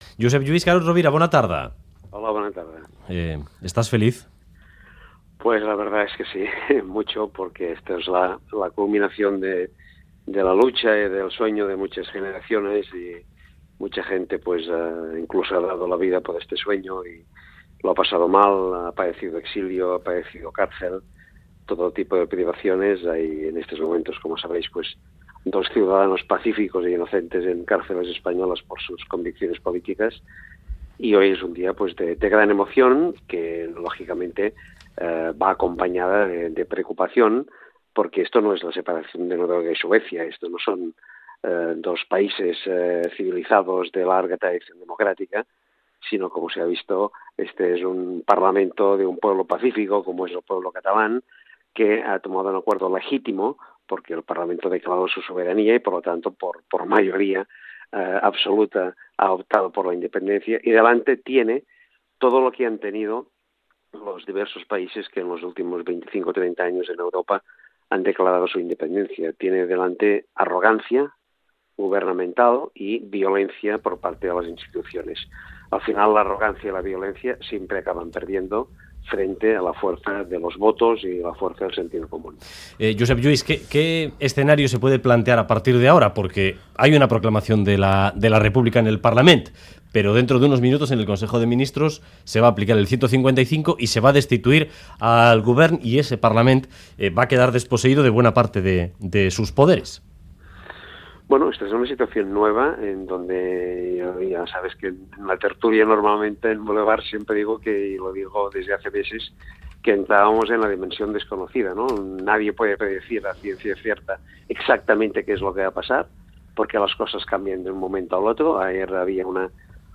Audio: Carod Rovira en declaraciones a Radio Euskadi dice que 'Esto es la culminación de la lucha' y 'Hoy es un día de gran emoción que va acompañado de preocupación'.